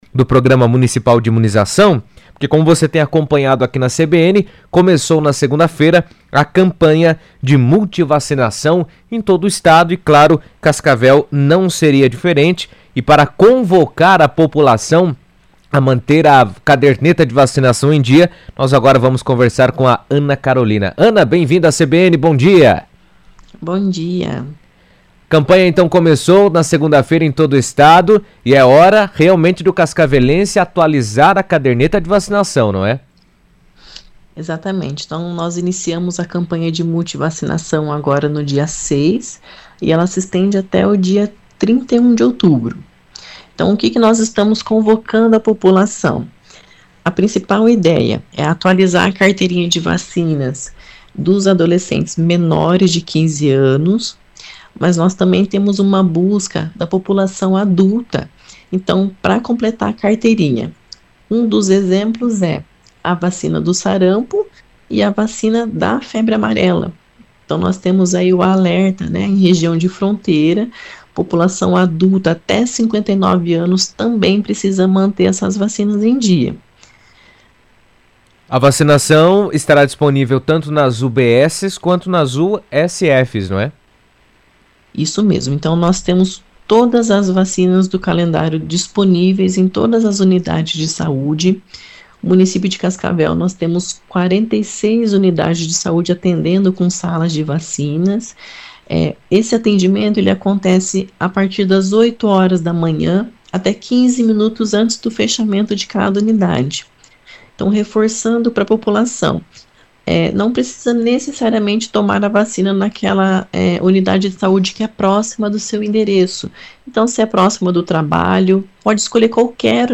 falou por telefone na CBN sobre a importância da ação, reforçando que manter a vacinação em dia é fundamental para a prevenção de diversas doenças.